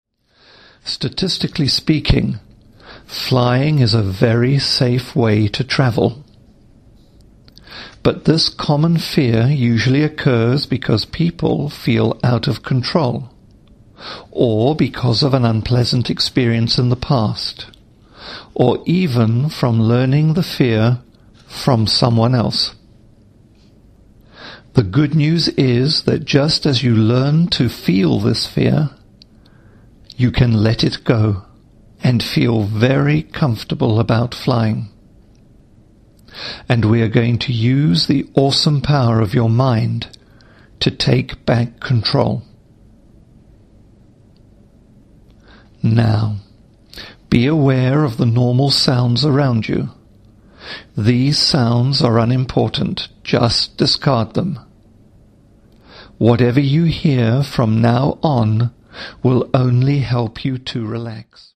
Audio Induction; Audio Affirmations;
No Music Ambience; No Nature Ambience
The Fear of Flying program contains only voice, and you will be guided through the session.